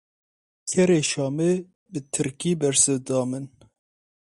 Pronounced as (IPA)
/tɪɾˈkiː/